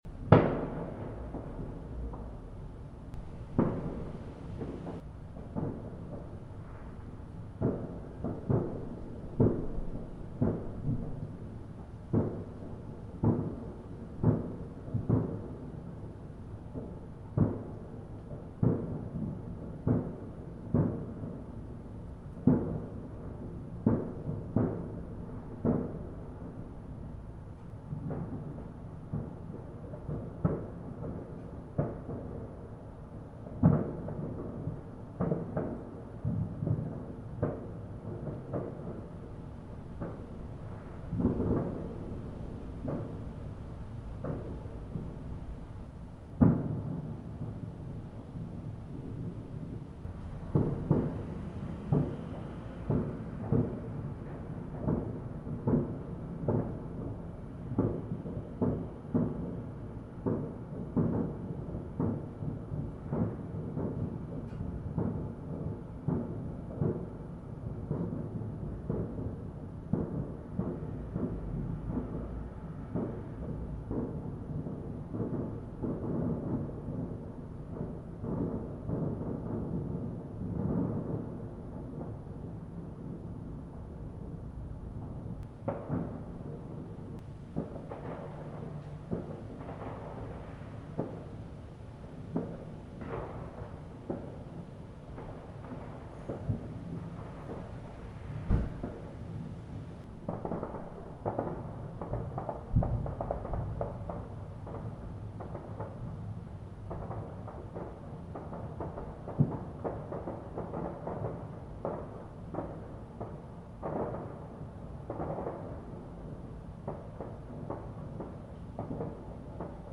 Firework Highlights 6 November 2021
A few minutes of edited highlights from the evening of 6 November.